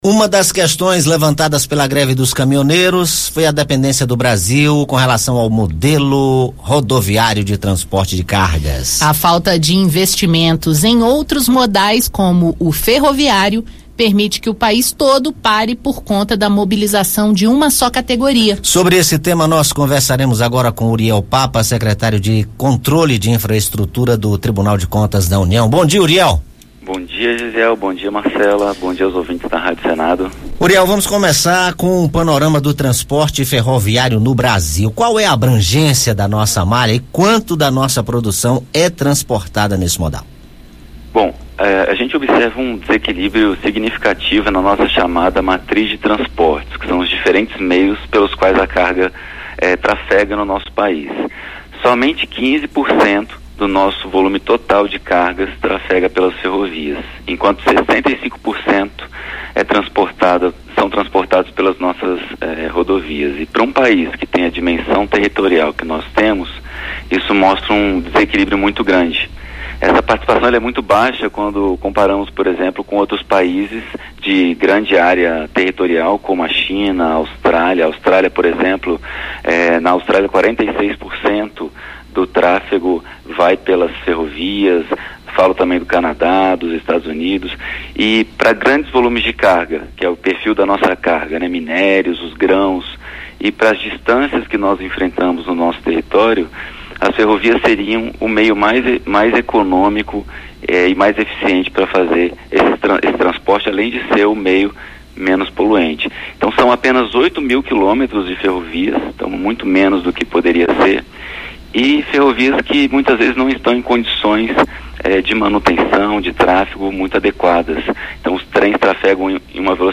Entrevista com Uriel de Almeida Papa, secretário de Controle de Infraestrutura do Tribunal de Contas da União.